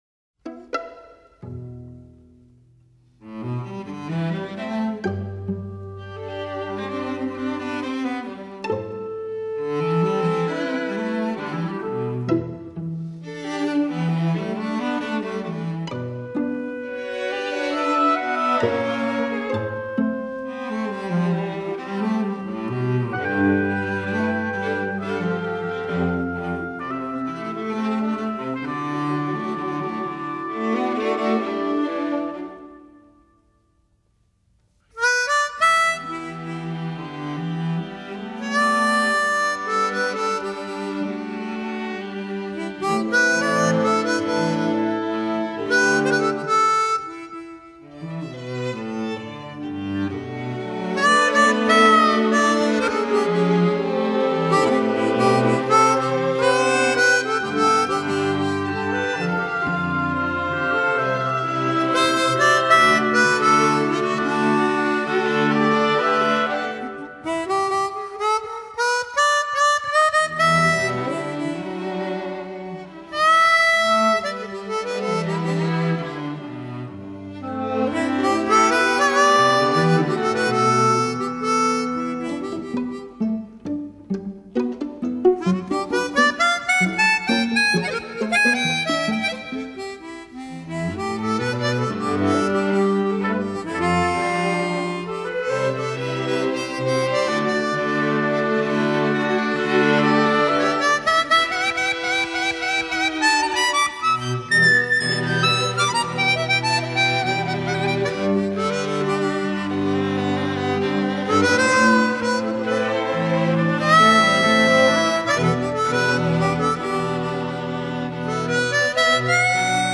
使用十二孔半音阶演奏